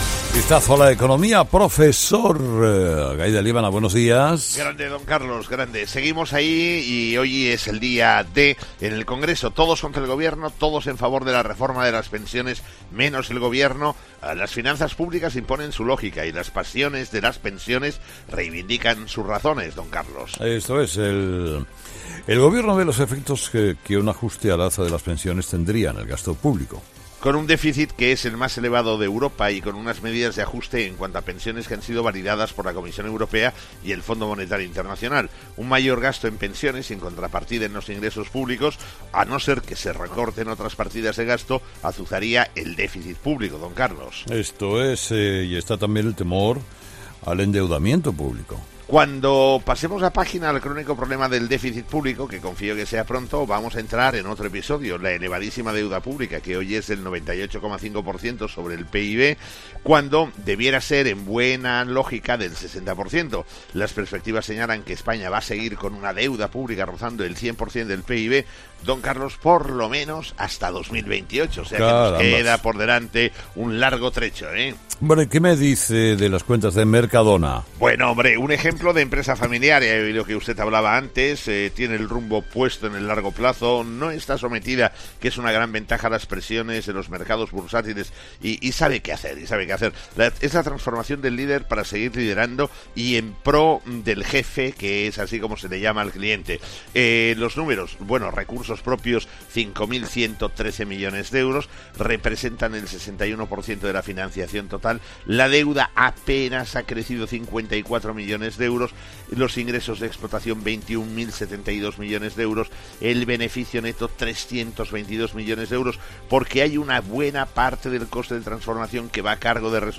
Escucha ahora a Gay de Liébana: “Un mayor gasto en pensiones sin contrapartida en los ingresos públicos, azuzaría el déficit público”, emitido el miércoles 14 de marzo en ‘Herrera en COPE’